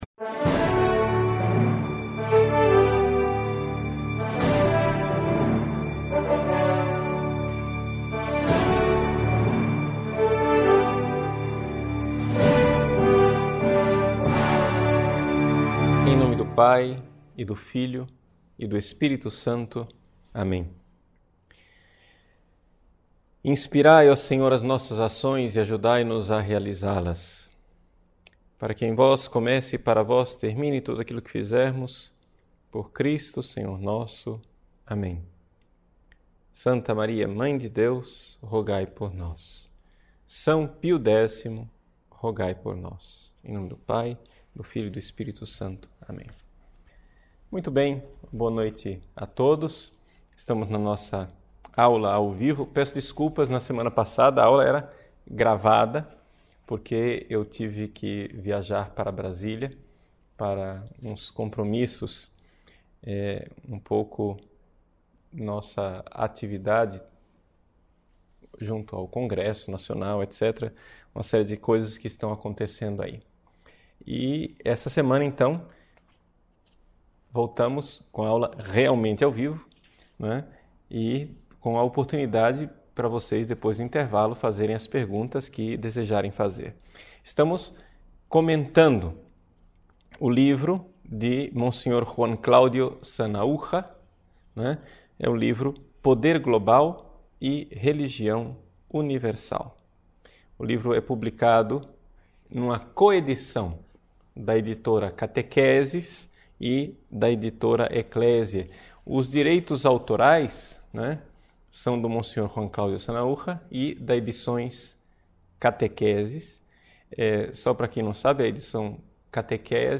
Nesta aula